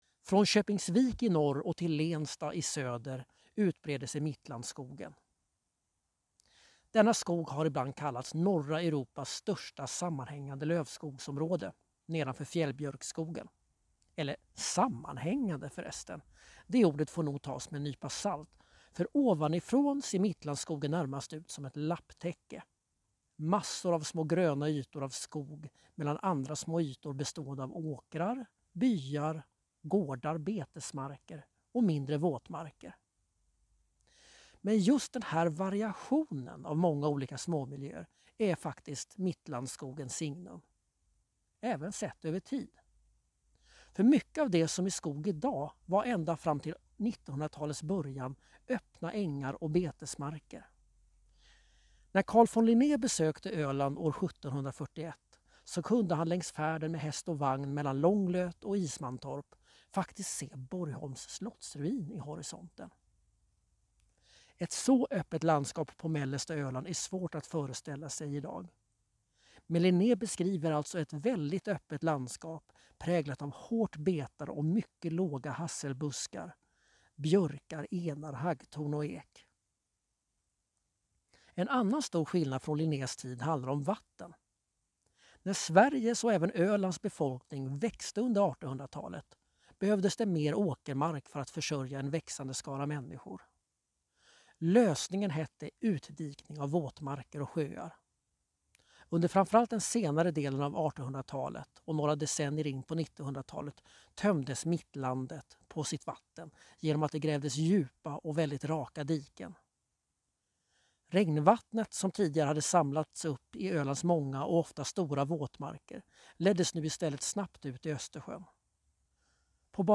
En vandring genom Mittlandet tar dig genom unika naturmiljöer och rik kulturhistorik. Lyssna på en berättelse om Mittlandet här!